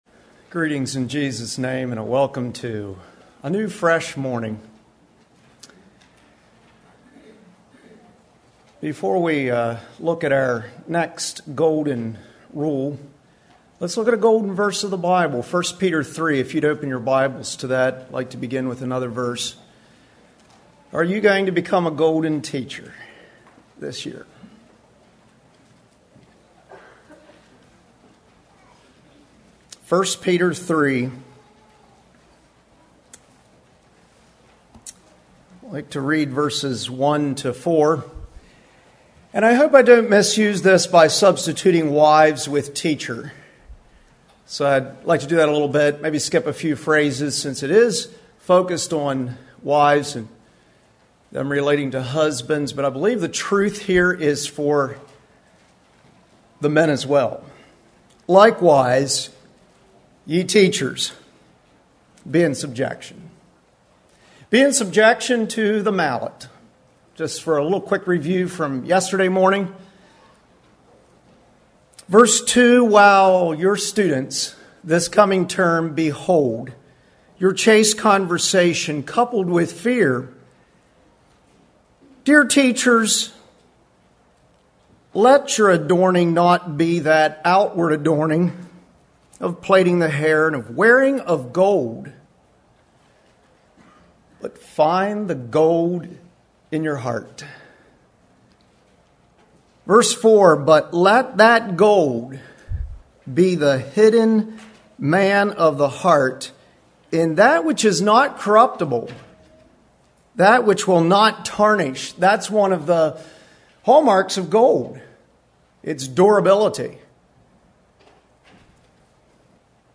Home » Lectures » Golden Rules for Teachers, Part 2